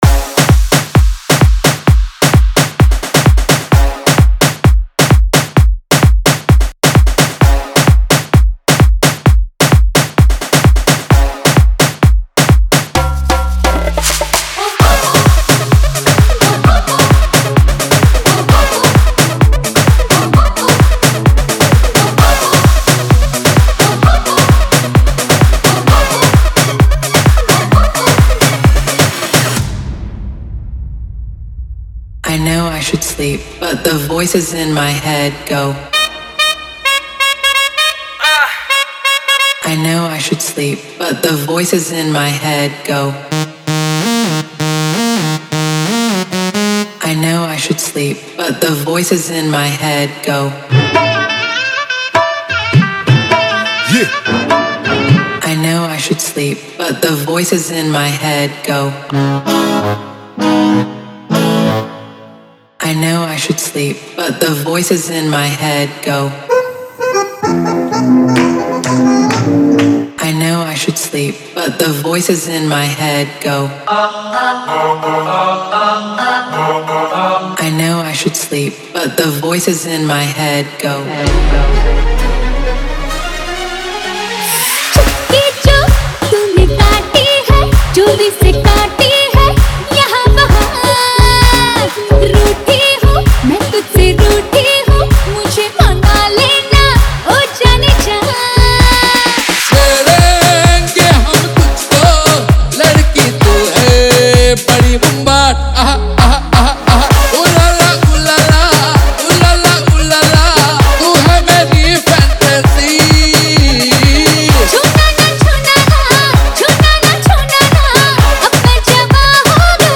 2026 Bollywood Single Remixes Song Name